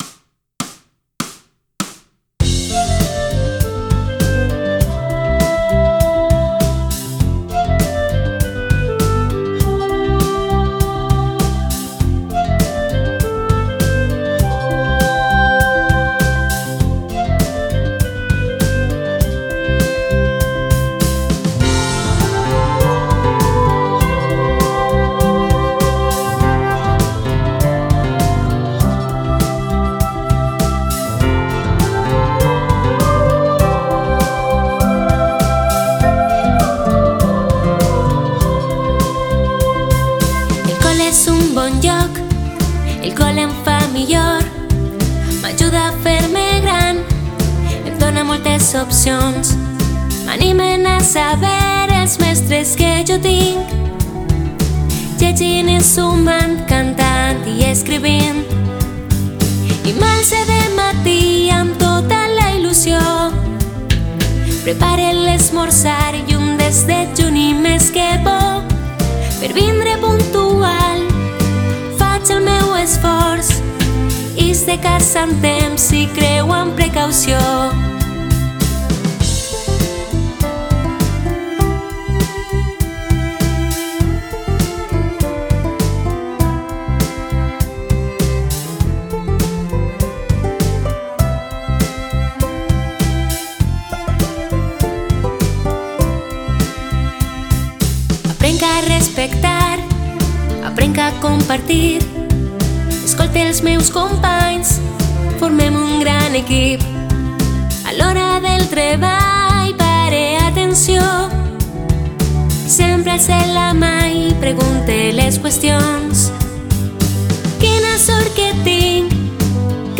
X Trobada Municipal de Cors de Centres Educatius